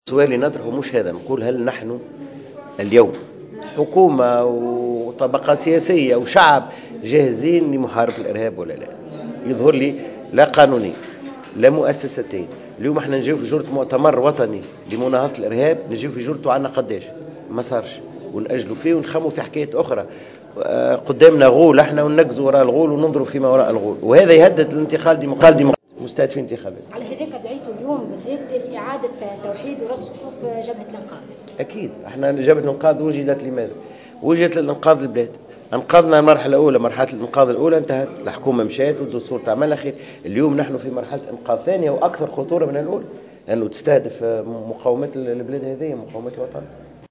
قال سمير بالطيب أمين عام حزب المسار الديمقراطي الاجتماعي في تصريح لجوهرة أف أم اليوم الخميس إن دور جبهة الإنقاذ في هذه المرحلة سيكون أكثر أهمية من دورها الذي لعبته في المرحلة المنقضية والتي توجت بإعلان الدستور وإسقاط حكومة الترويكا وتشكيل حكومة كفاءات.